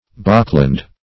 Bockland \Bock"land\, n.